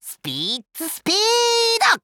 Spitz's voice from the official Japanese site for WarioWare: Move It!
WWMI_JP_Site_Spitz_Voice.wav